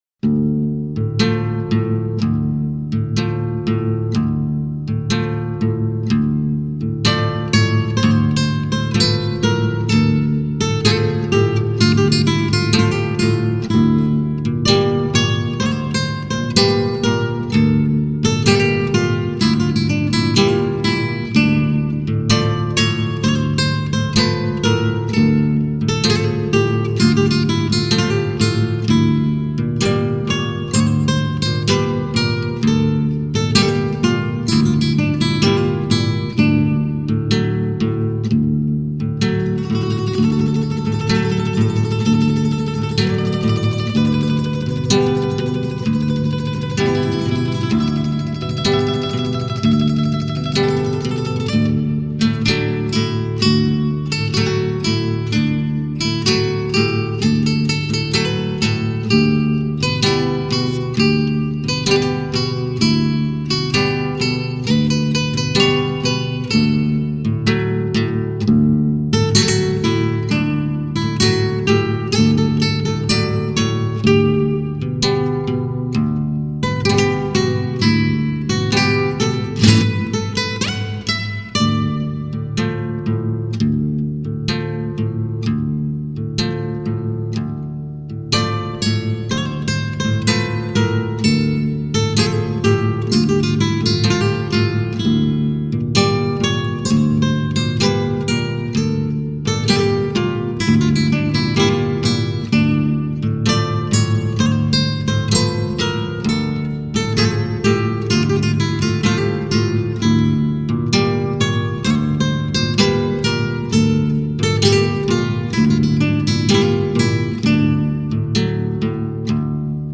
木吉他